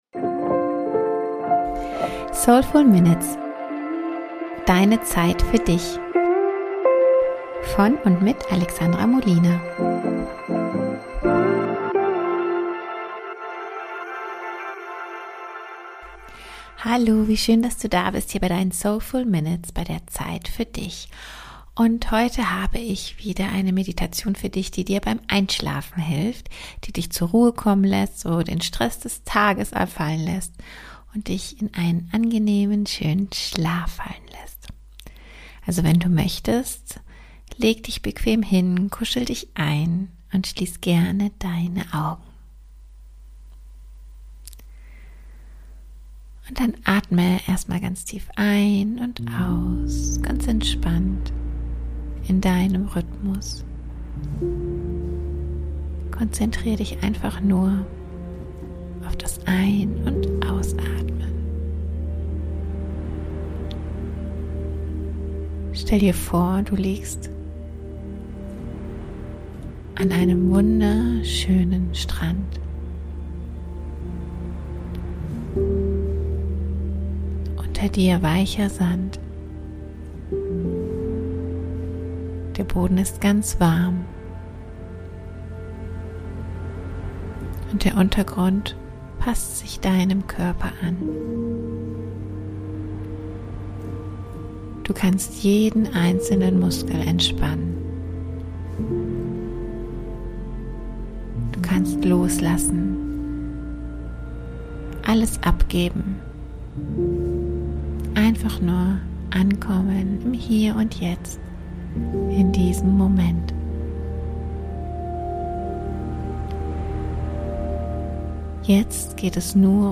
Mach es dir gemütlich und genieße eine neue Einschlafmeditation.